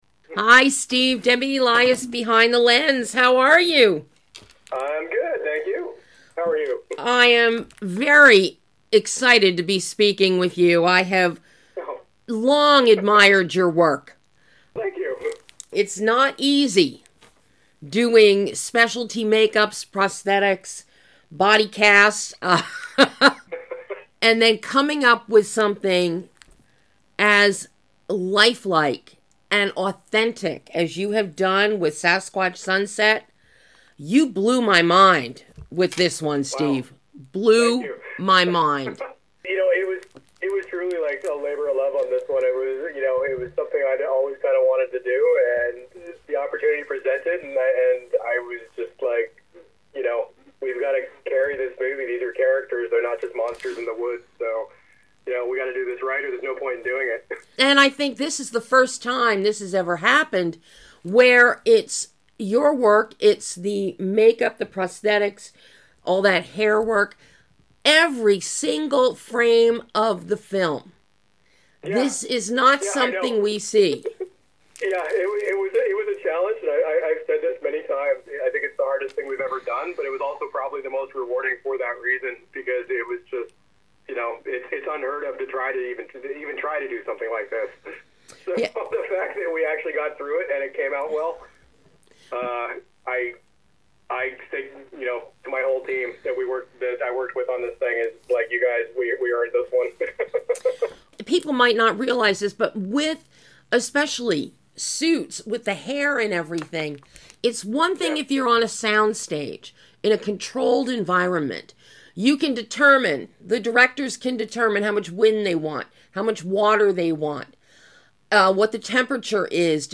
SASQUATCH SUNSET - Exclusive Interview